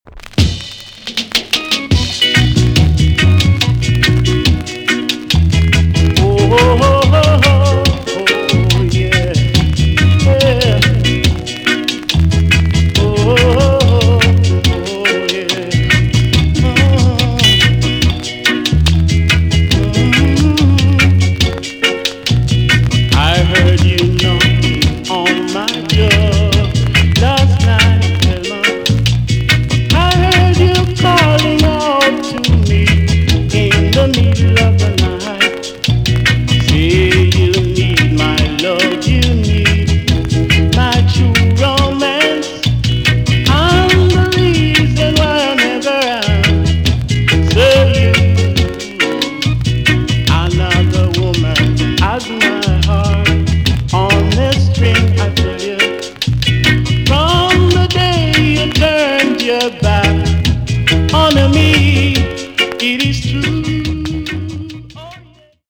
VG+ 少し軽いチリノイズが入ります。
NICE ROCK STEADY TUNE!!